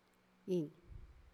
次の話者の発音を聞いてみてください。
また、今回の我々のデータは、宮古語池間方言を話す一部の地域の話者（西原地区の話者）、しかも７０歳以上の話者のデータしか扱っていないことも特記に値するでしょう。
dog_005_KA_word.wav